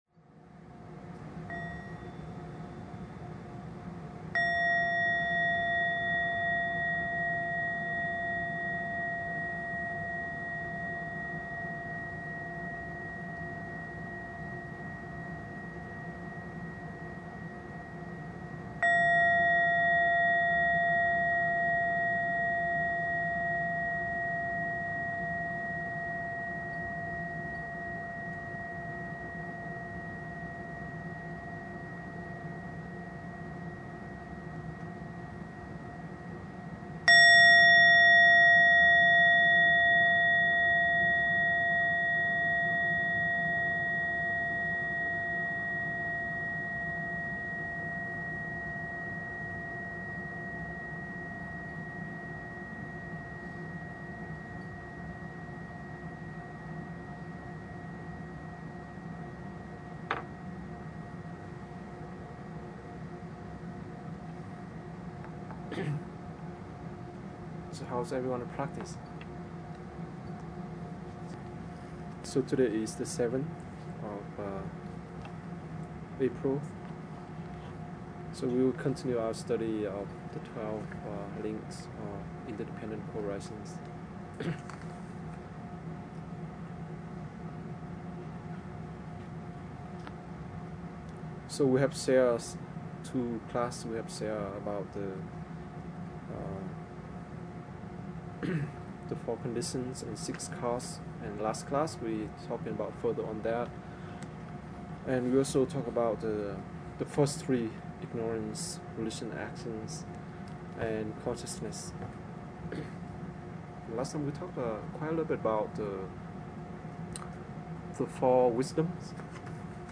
Dharma Talk 4/7/2009